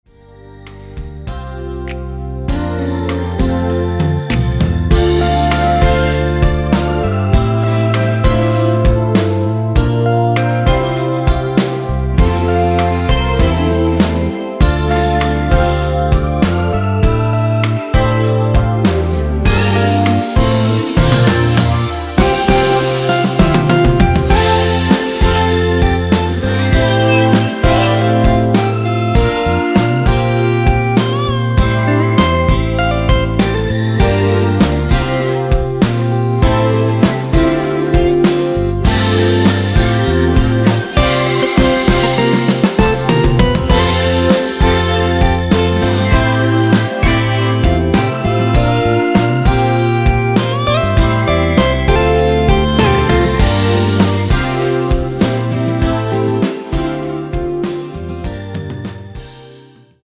원키에서(+3)올린 코러스 포함된 MR입니다.(미리듣기 확인)
앞부분30초, 뒷부분30초씩 편집해서 올려 드리고 있습니다.
중간에 음이 끈어지고 다시 나오는 이유는